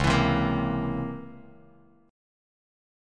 C#mM13 chord